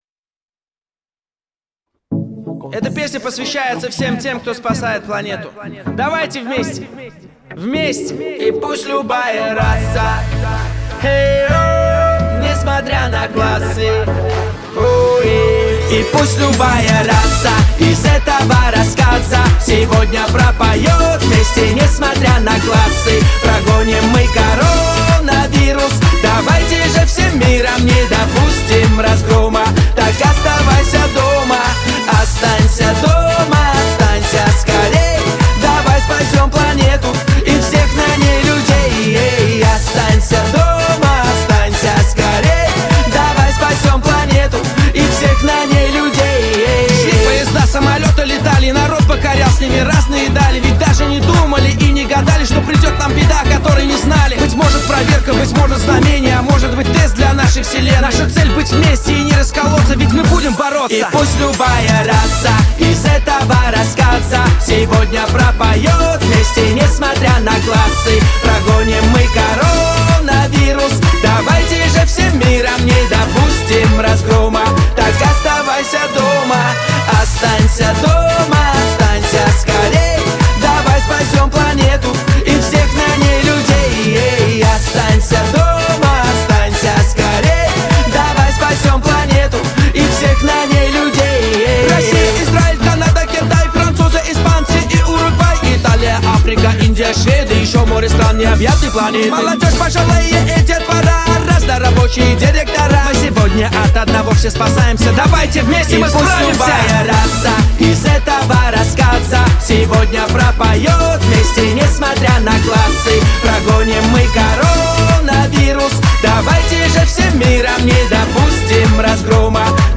это яркий пример современного хип-хопа